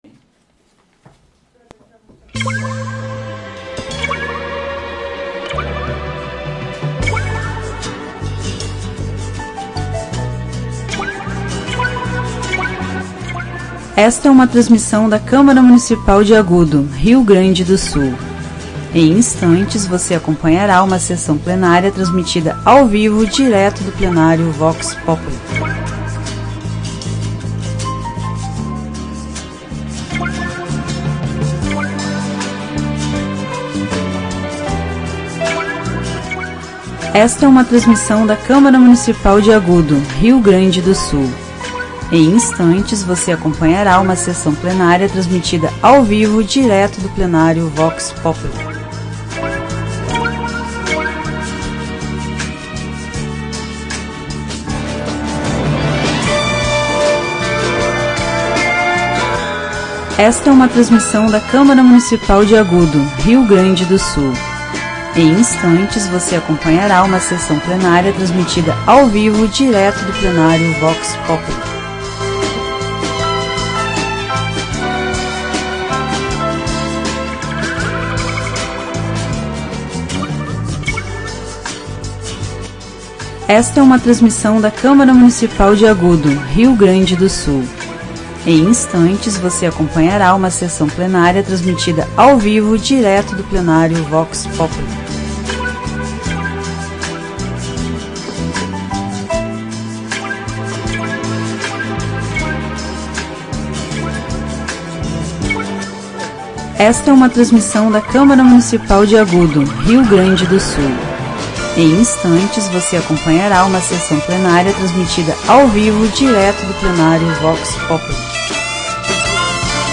Áudio da 52ª Sessão Plenária Ordinária da 17ª Legislatura, de 20 de abril de 2026